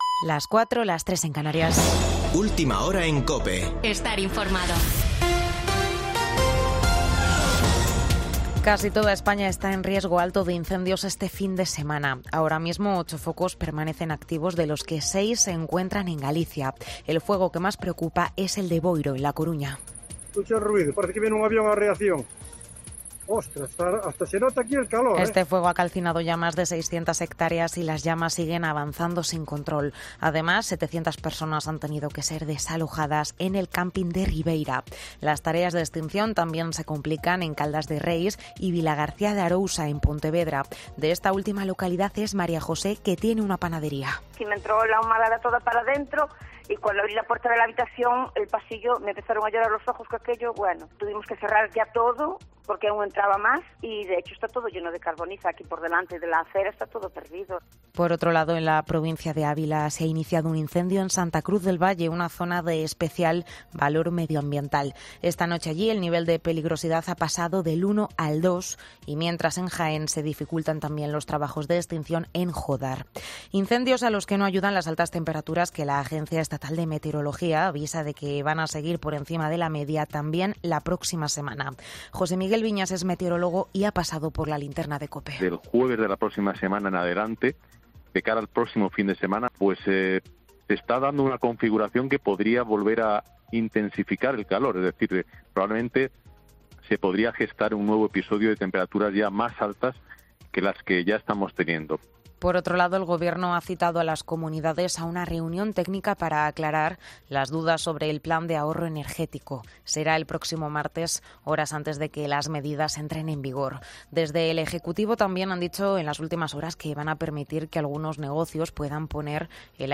Boletín de noticias de COPE del 6 de agosto de 2022 a las 04.00 horas